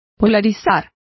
Complete with pronunciation of the translation of polarized.